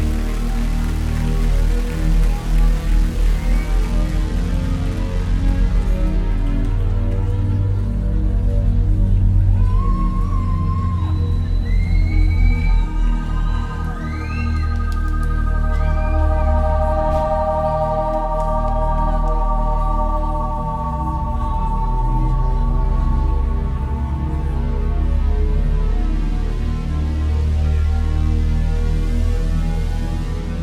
0 => "Chanson francophone"